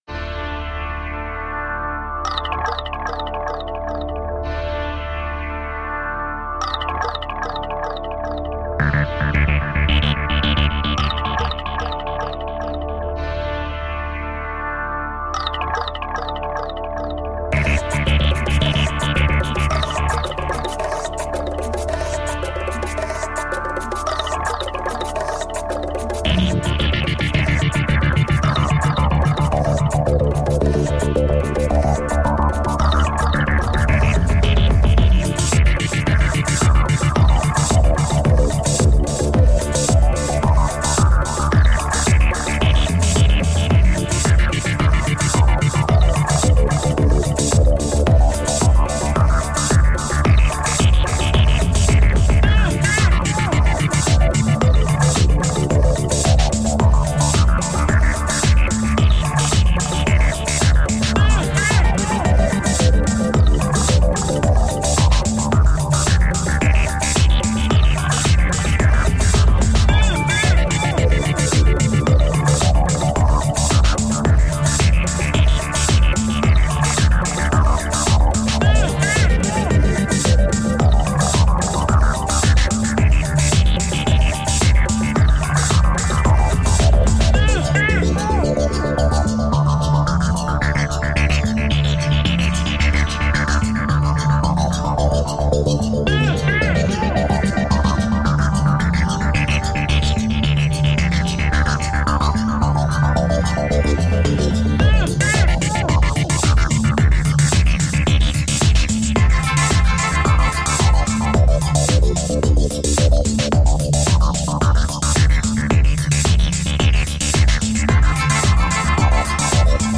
Urban electronic music.